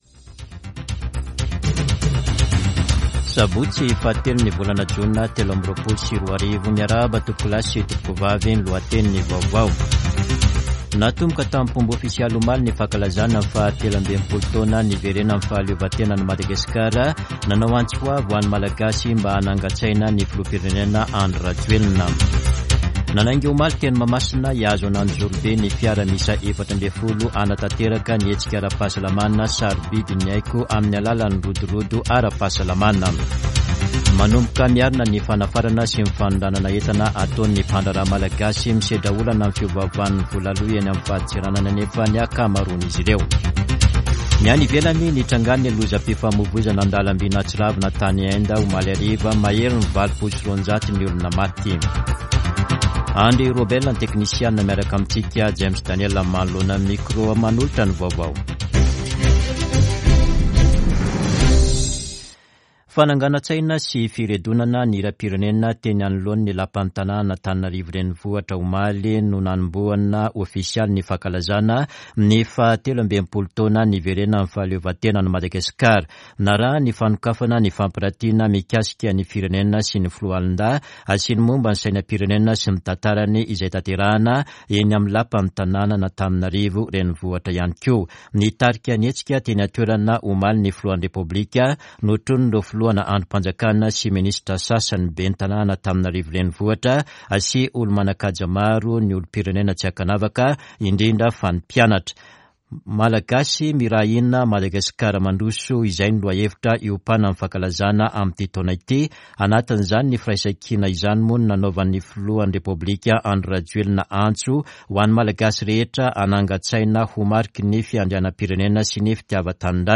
[Vaovao maraina] Sabotsy 3 jona 2023